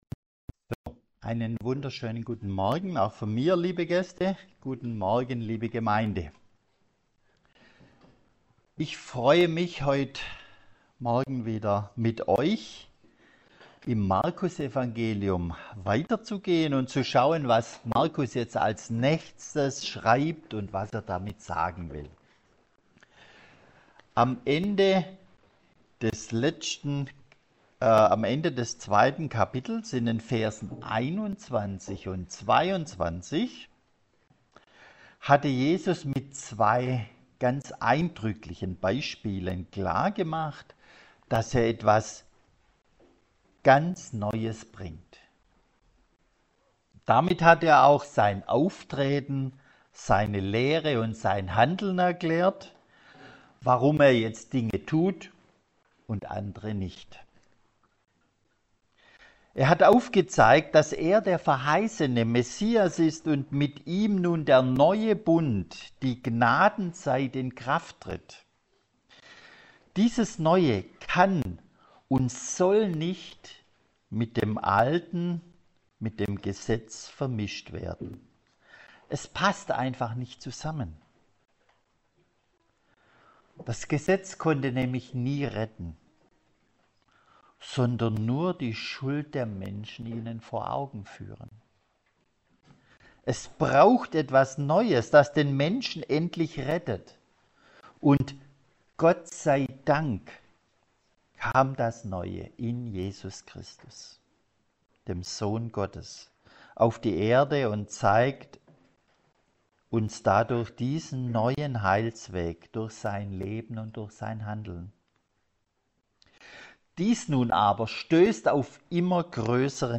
Predigtreihe: Markusevangelium